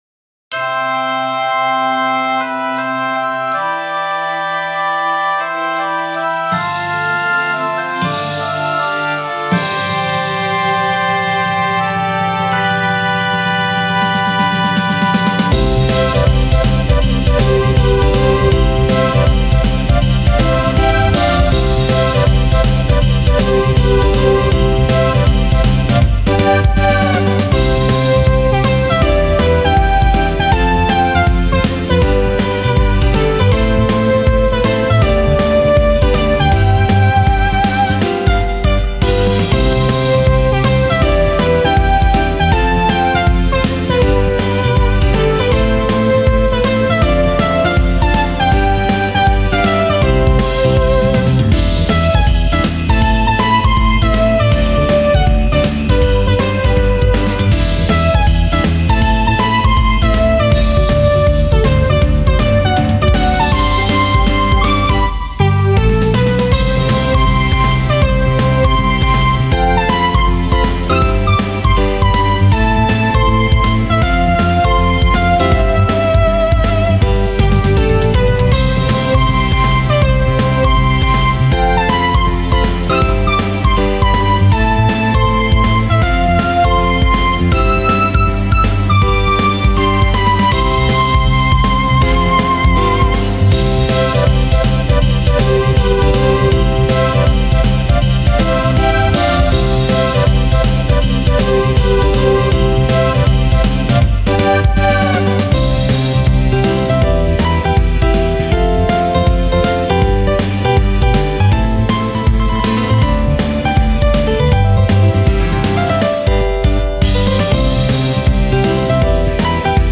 インスト ポップス スウィング
青空っぽい